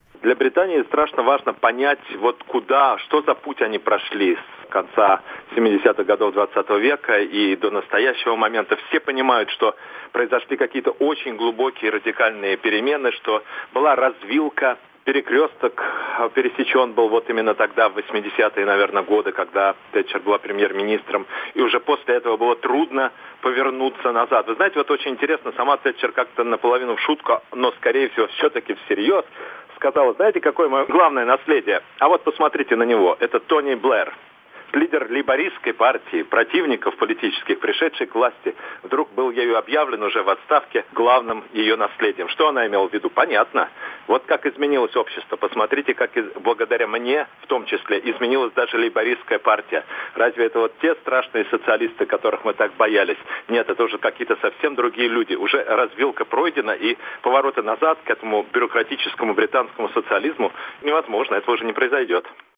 Политический комментатор